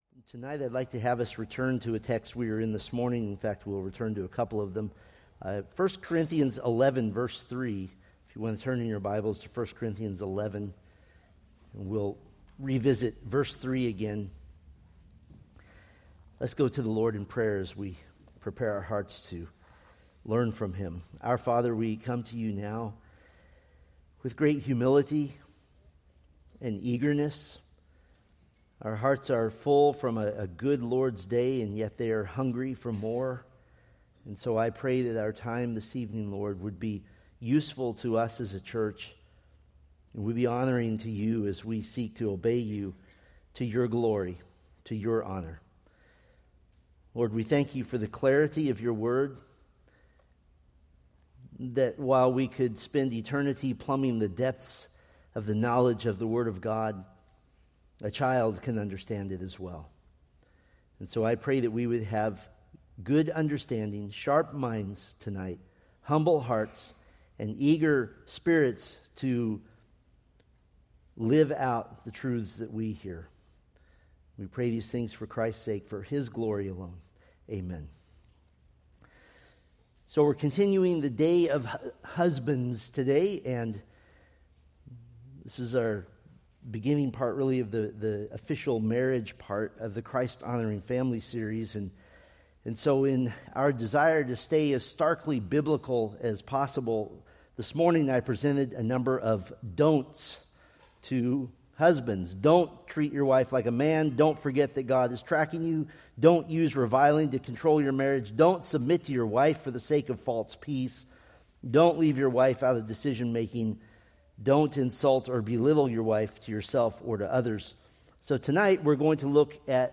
Preached August 3, 2025 from Selected Scriptures